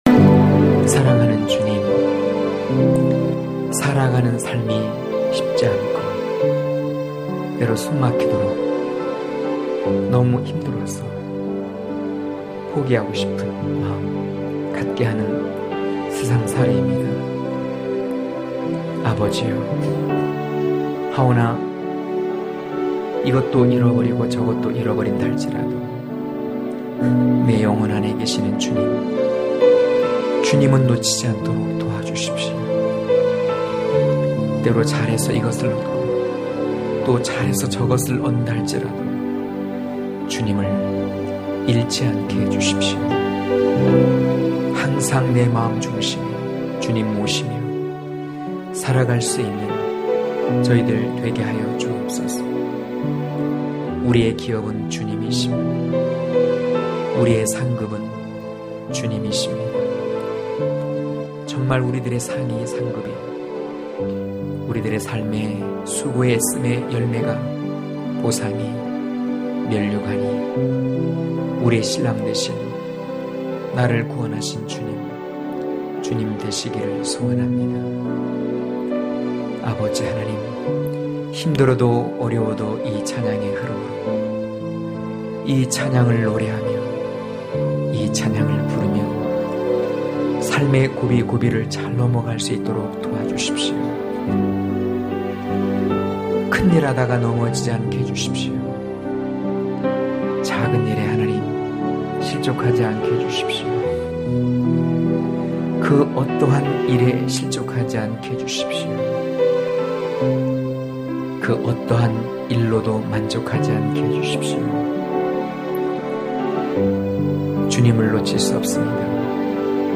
강해설교 - 13.몰약의 사랑은 몰약으로(아5장1-8절)